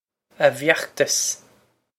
a vyokh-tos
This is an approximate phonetic pronunciation of the phrase.